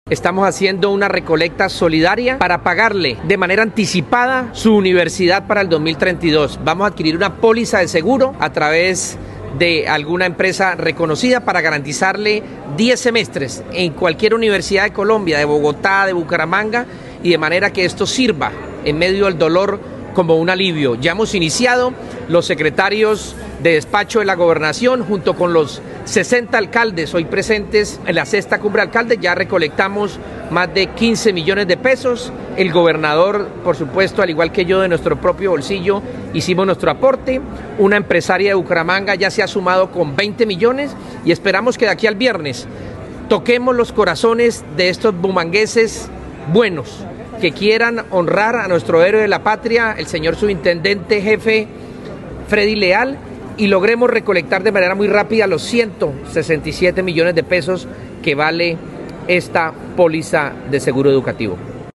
Javier Sarmiento, alcalde designado de Bucaramanga